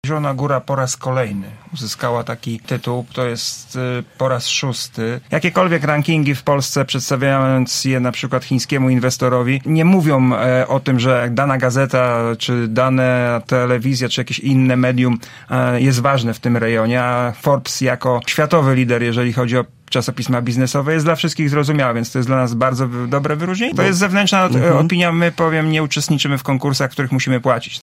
Tydzień temu statuetkę dla naszego miasta odebrał wiceprezydent Zielonej Góry, Dariusz Lesicki: